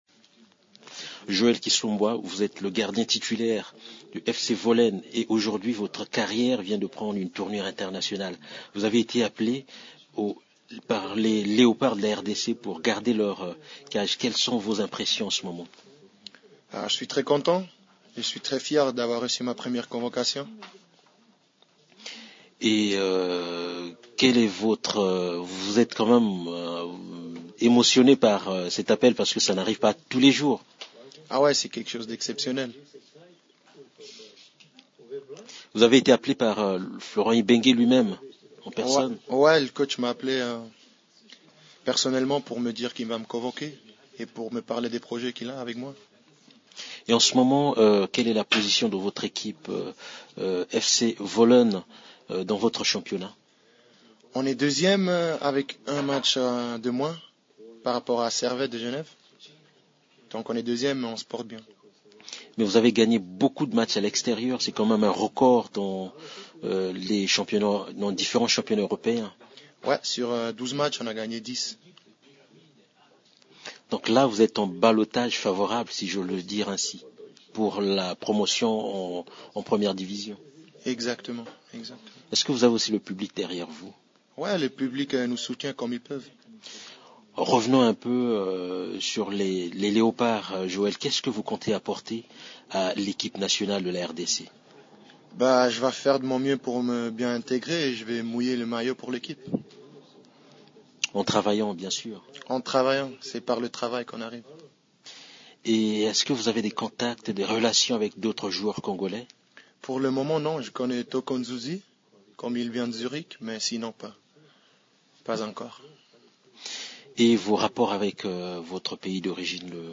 Vous pouvez écouter l’intégralité de l’entretien que le gardien de Wohlen a accordé à Radio Okapi.